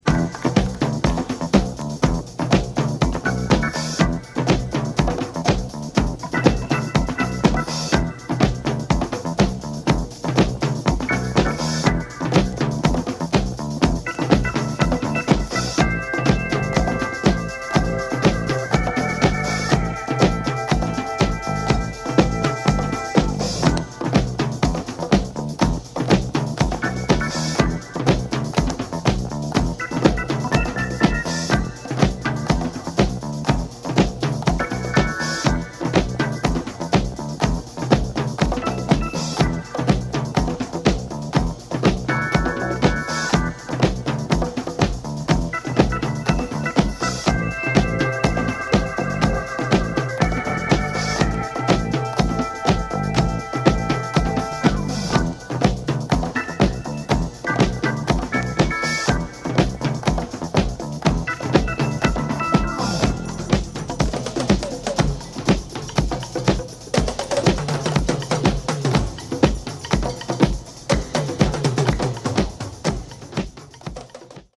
The tribal-disco sound of Canada.